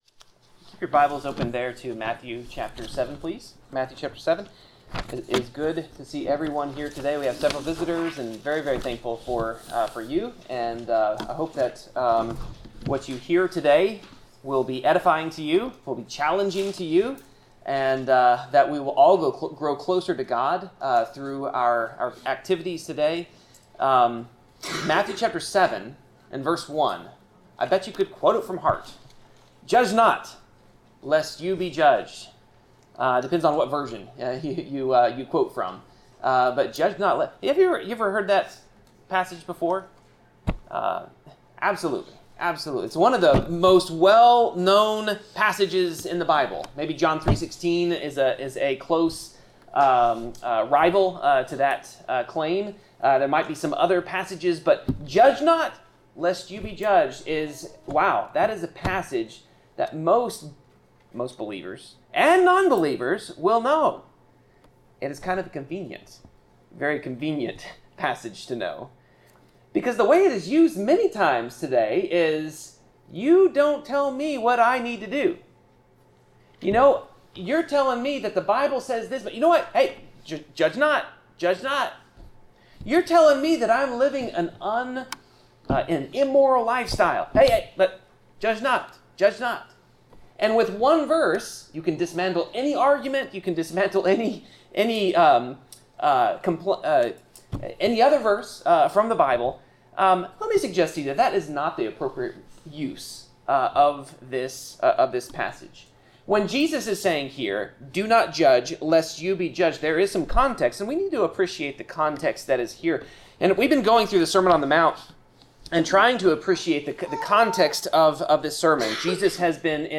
Passage: Matthew 7:1-12 Service Type: Sermon